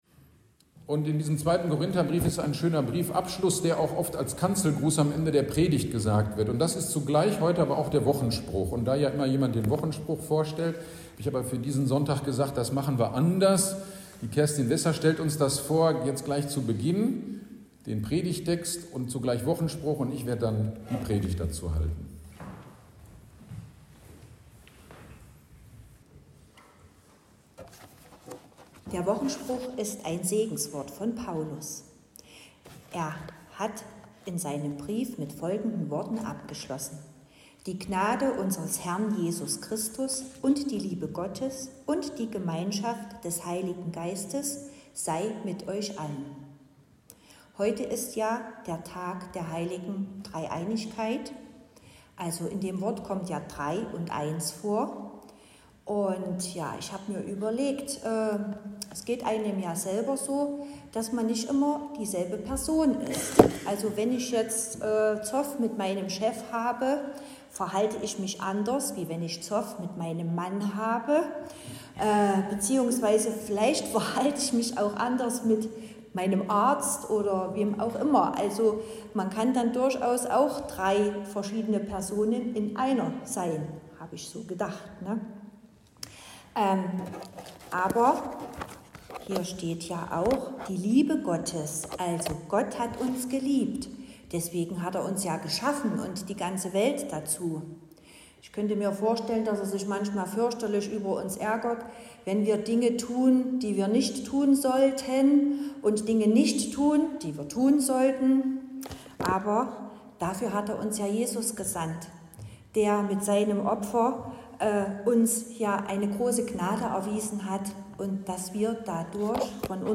GD am 15.06.2025 Predigt zu 2. Korinther 13, 13